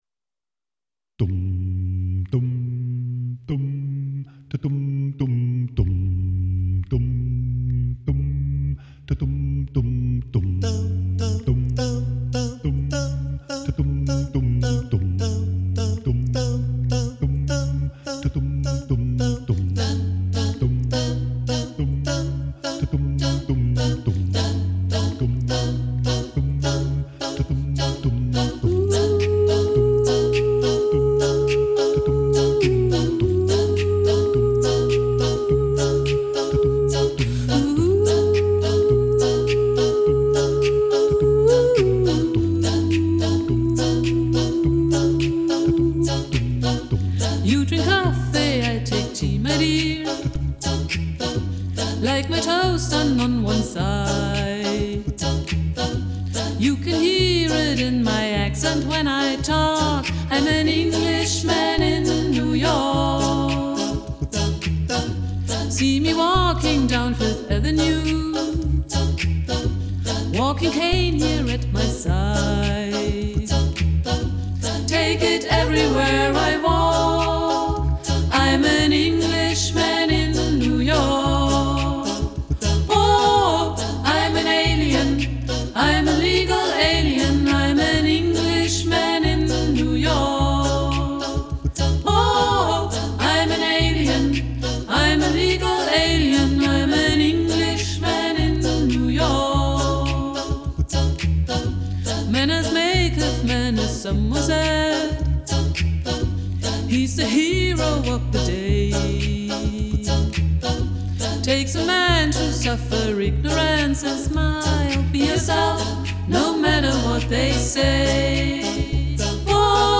a‑cappella aus Augsburg